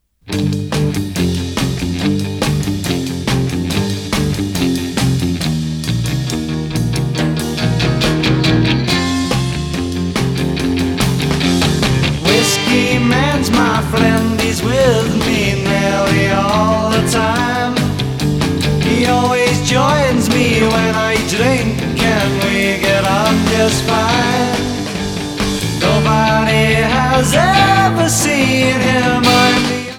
This mastering is well-balanced and super clean.